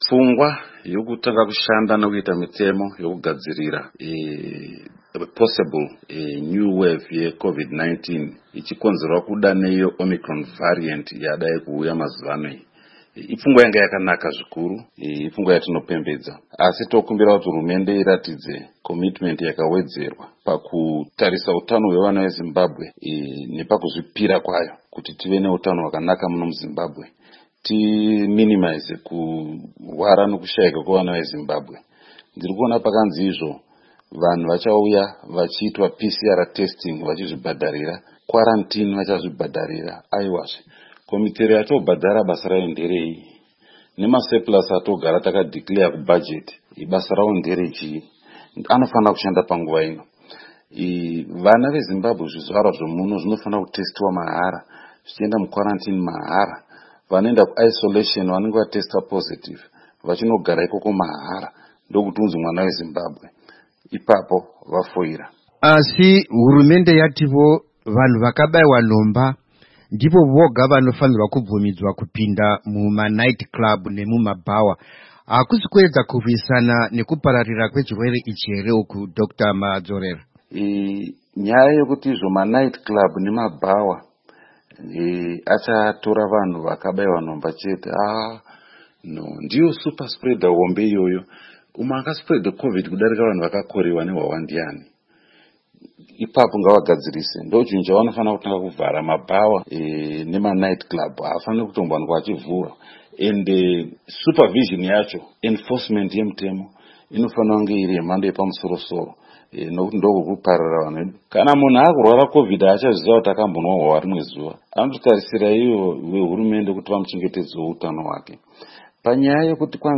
Hurukuro naDr Henry Madzorera